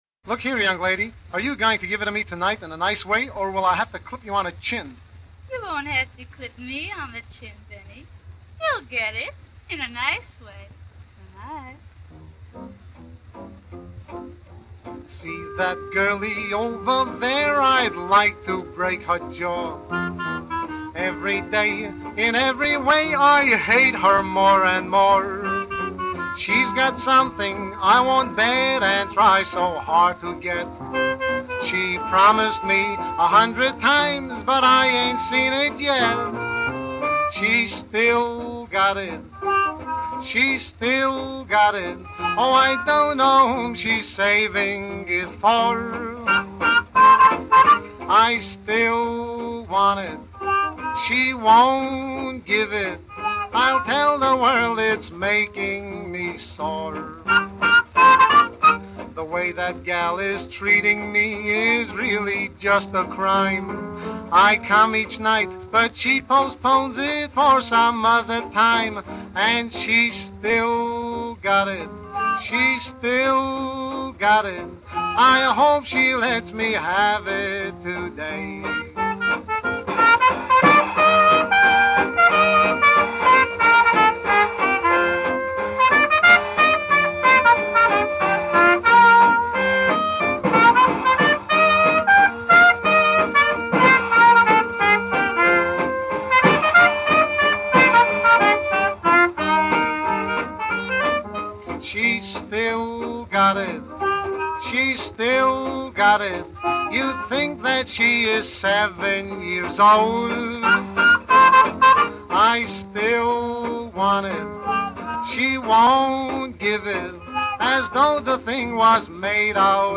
double entendre party record